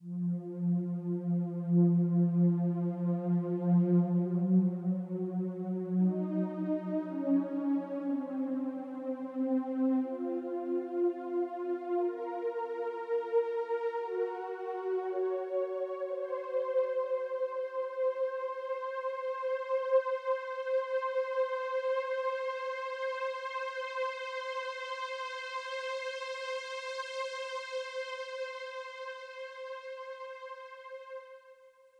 退出舞台东垫 120bpm 16bars
描述：用我的Novation Ultranova合成器制作的循环。
这些循环具有亚洲风味，可用于各种电子甚至HipHop类型。
Tag: 120 bpm Electronic Loops Pad Loops 2.69 MB wav Key : Unknown